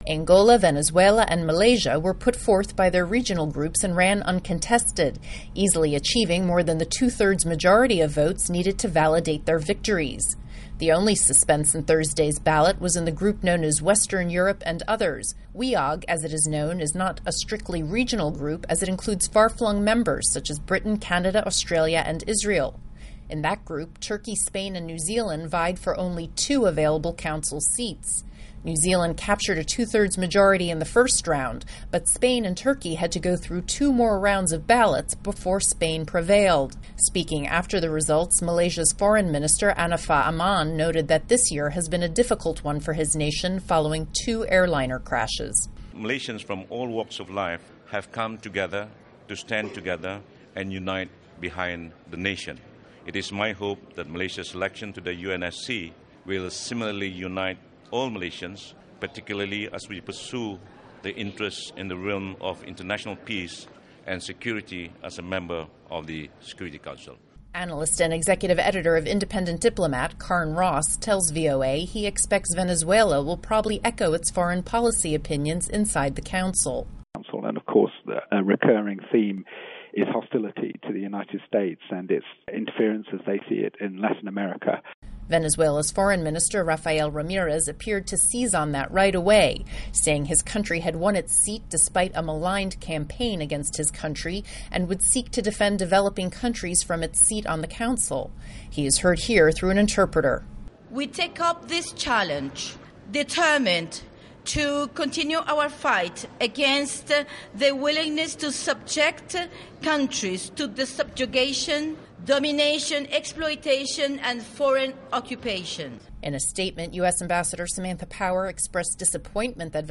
Angola/UN - Report